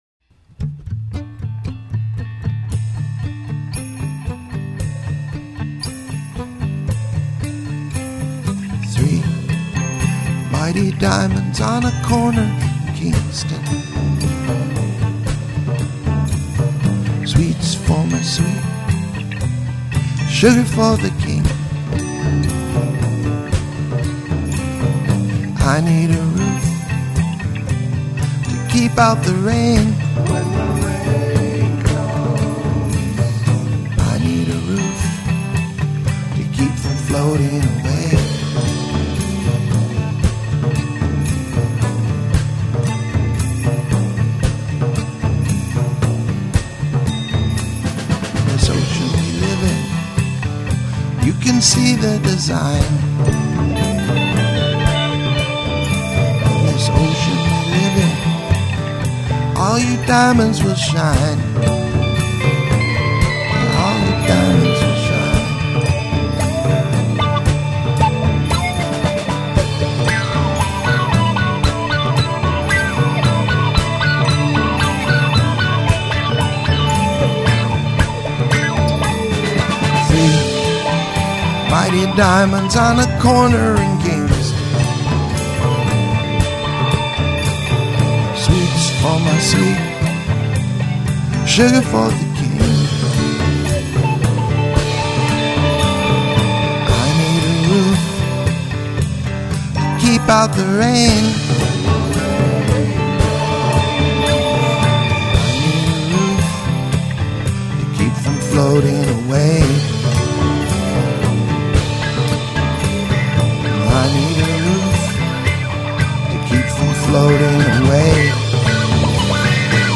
Capturing love and longing